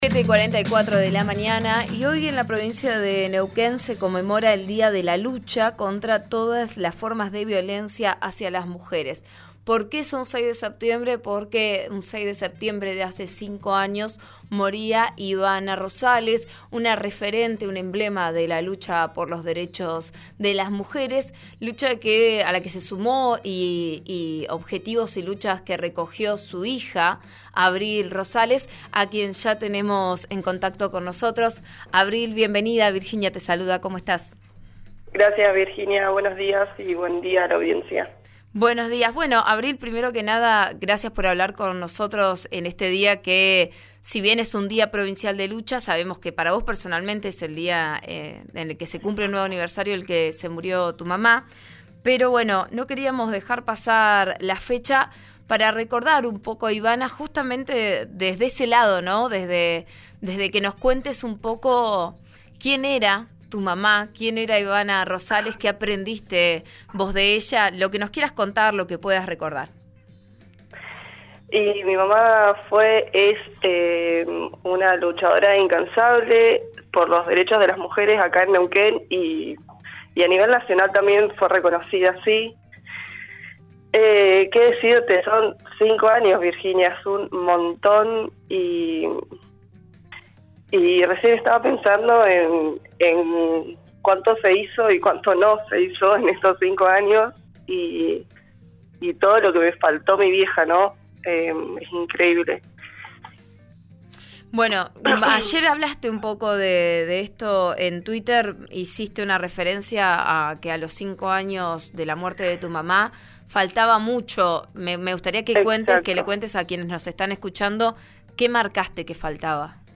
en diálogo con «Vos a Diario»